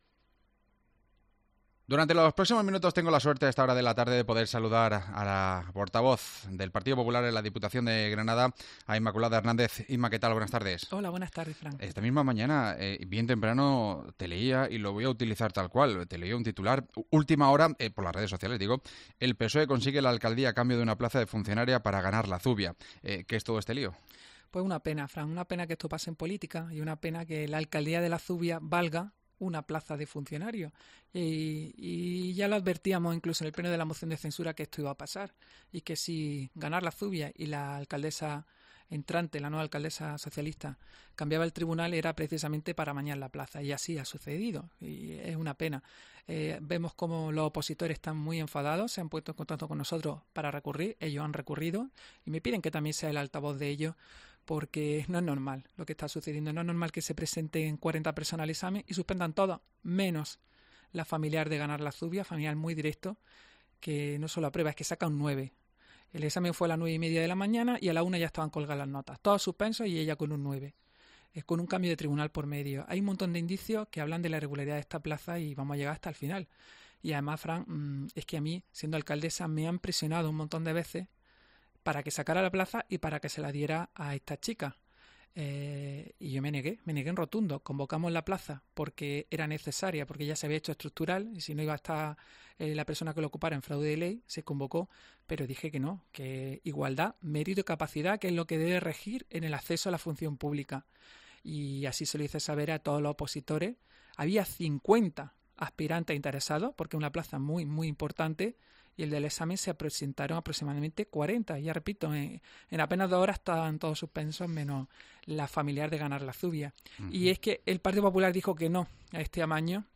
La exalcaldesa de La Zubia habla del escándalo de una oposición en su municipio cuya plaza ha recaído en un familiar de un concejal de 'Ganar La Zubia'